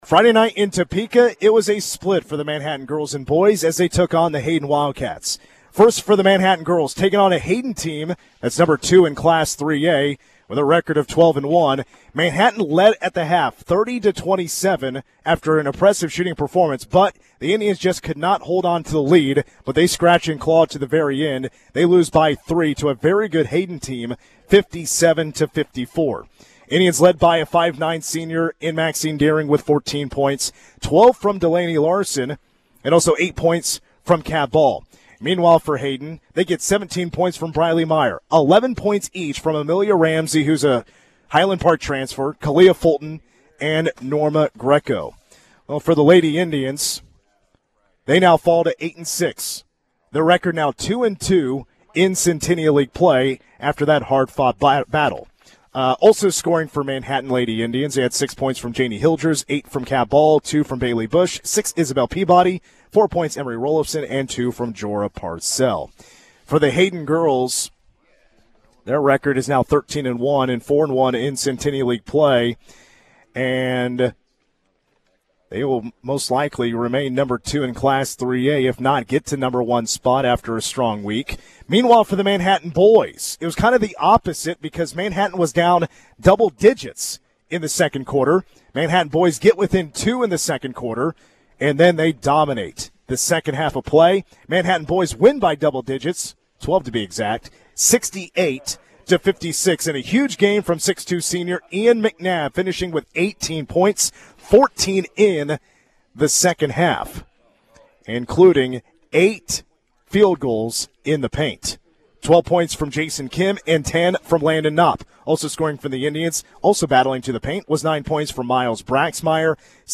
Complete recap